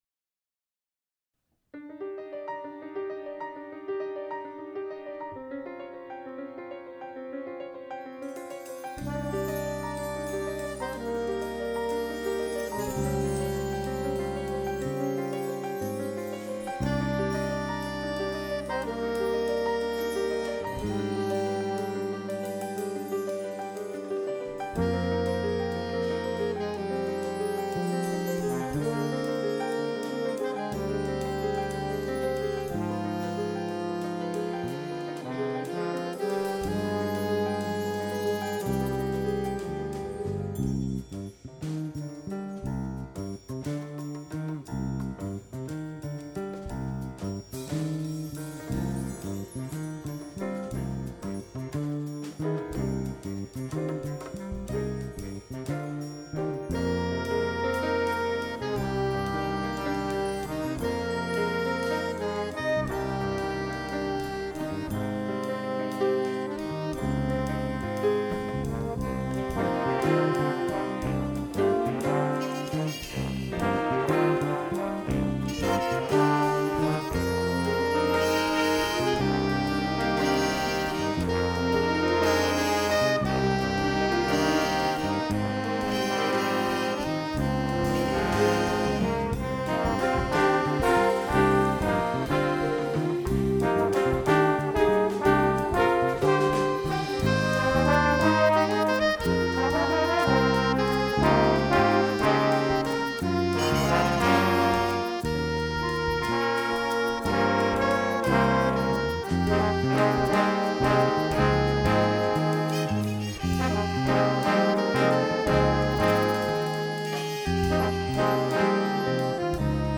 MVHS JAZZ ENSEMBLE PROJECTS BY YEAR
trombone
soprano sax